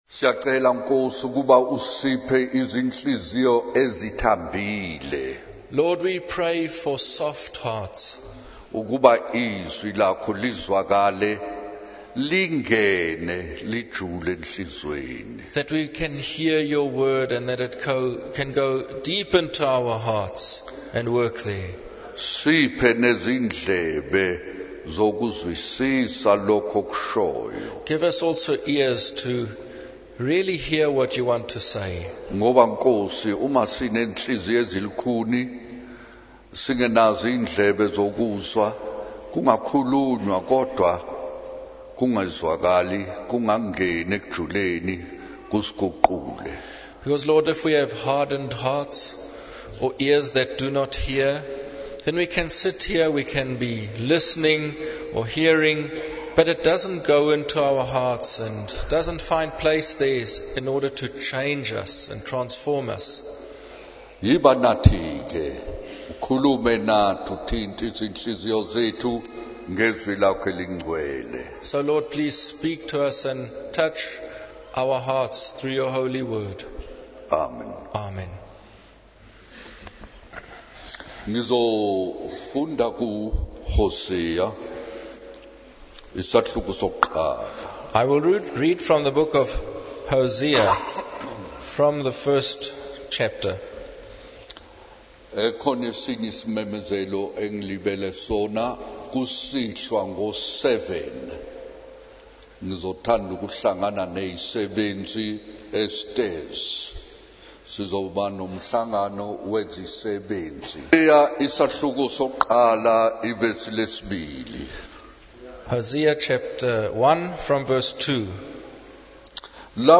In this sermon, the preacher emphasizes the importance of having a strong relationship with the Lord Jesus. He highlights the harmony and peace that comes from being filled with the Holy Spirit. The preacher warns against compromising one's Christian lifestyle and adopting the ways of the world, as it can lead to being separated from God.